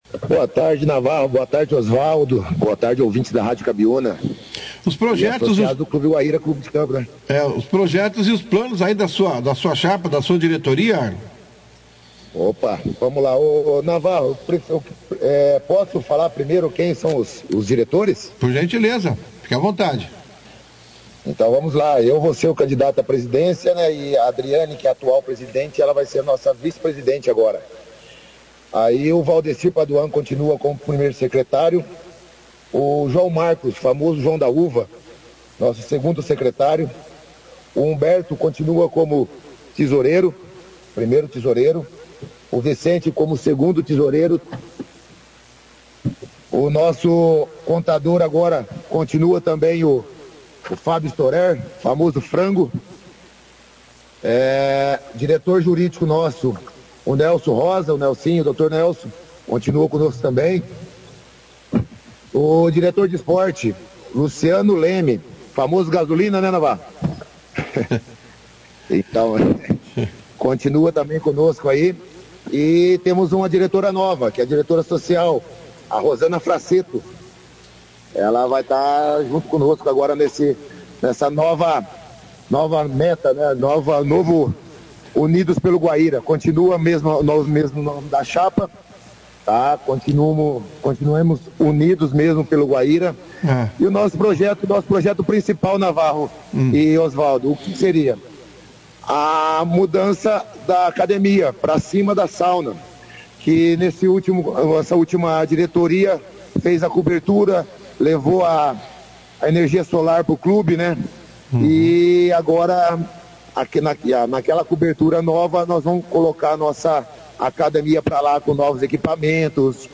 Em uma entrevista concedida ao jornal Operação Cidade nesta sexta-feira